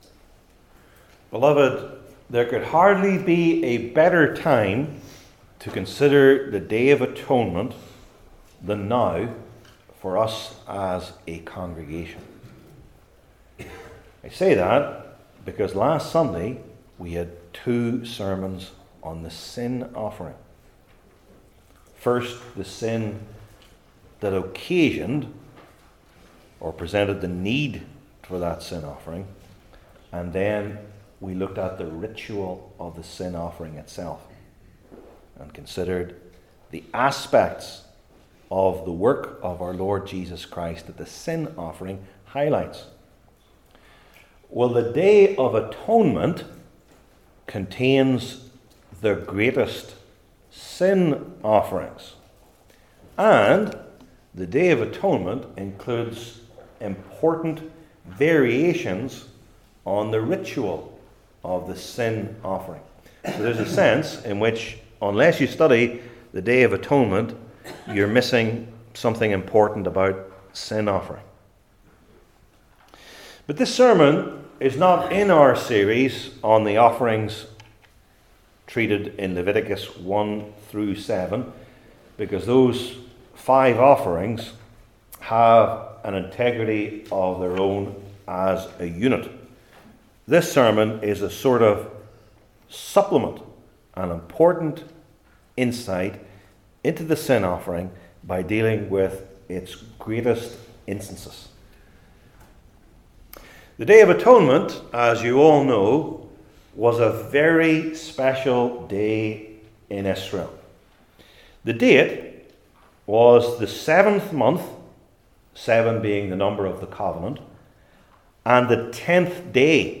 Leviticus 16 Service Type: Old Testament Individual Sermons I. The Atonement for the High Priest II.